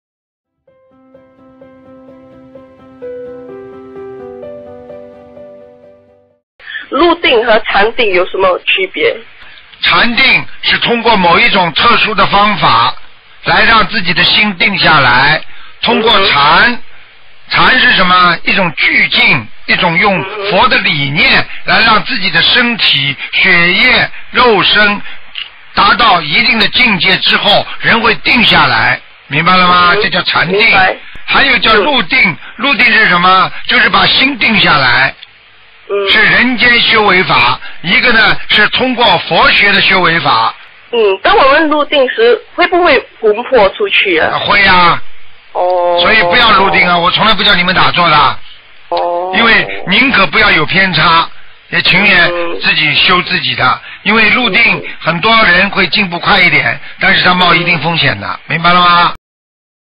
问答2013年12月13日！